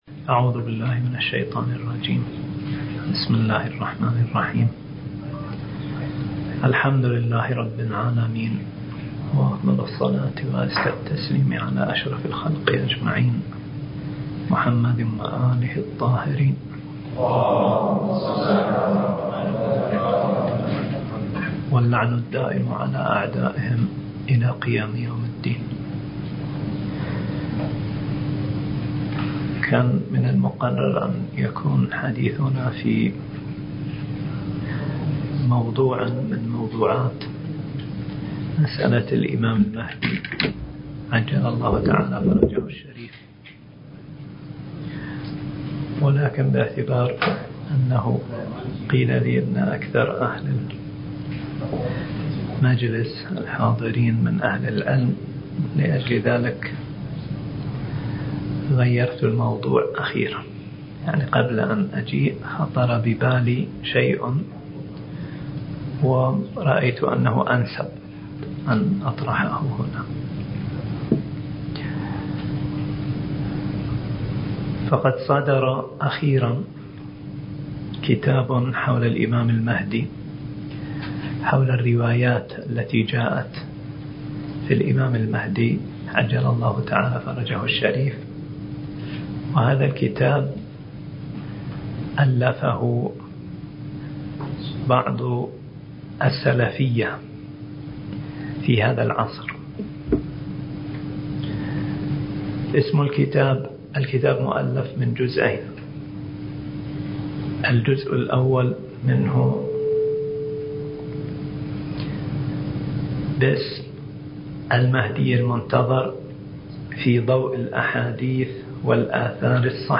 المحاضرة الثامنة عشر
المكان: النجف الأشرف